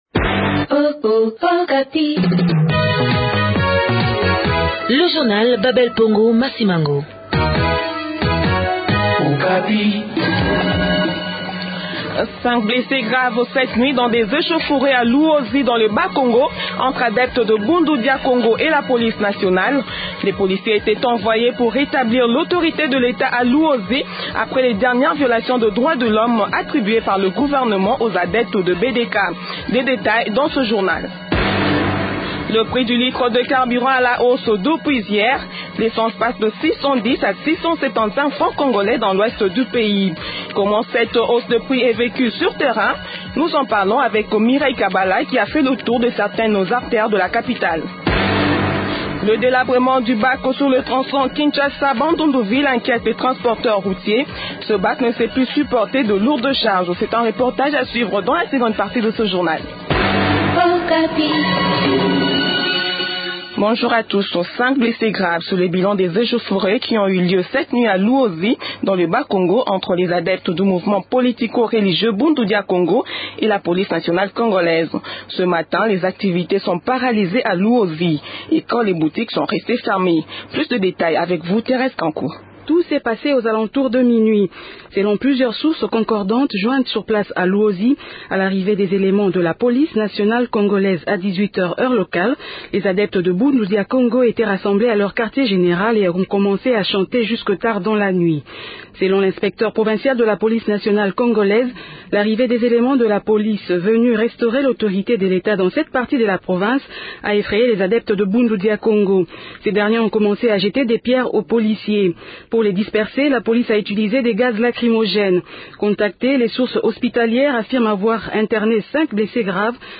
C’est un reportage a suivre dans la seconde partie de ce journal.